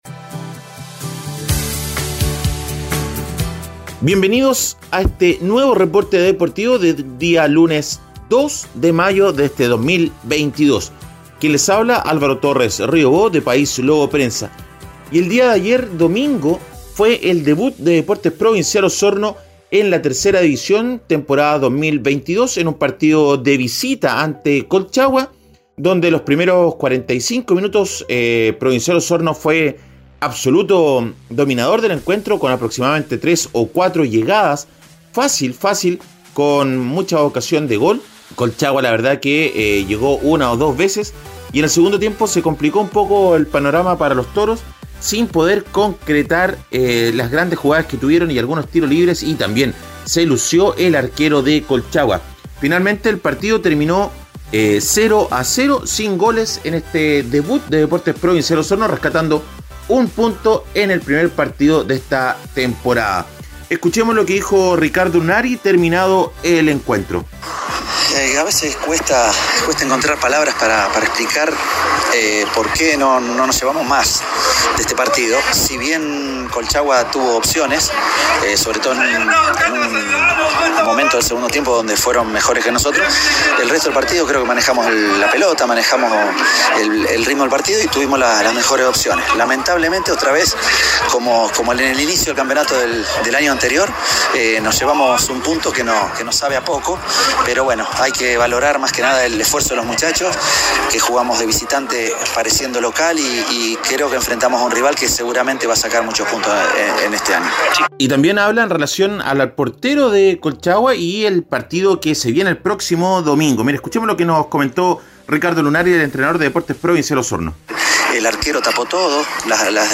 Reporte Deportivo ▶ Podcast 02 de mayo de 2022